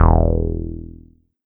PILLS BASS 1.wav